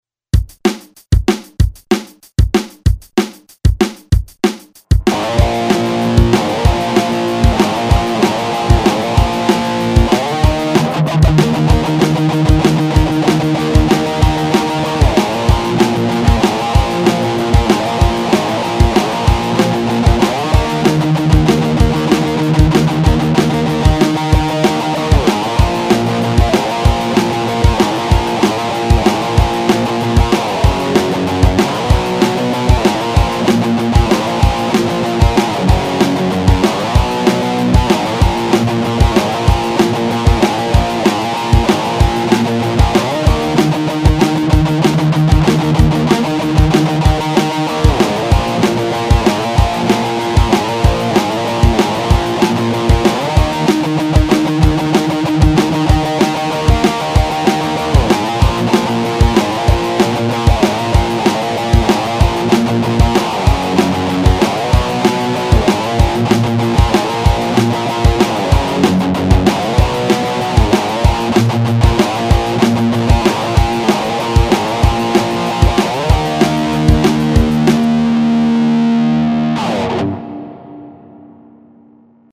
Издевательство над гитарой.
гитара музыка